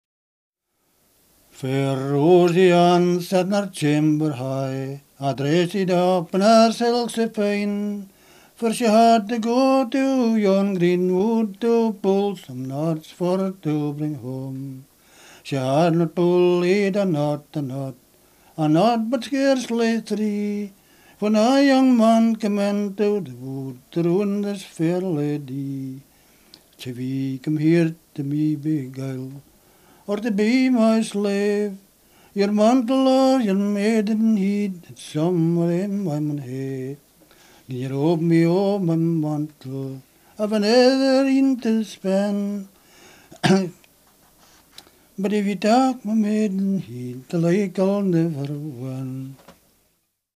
BALLATE DA INGHILTERRA, SCOZIA E IRLANDA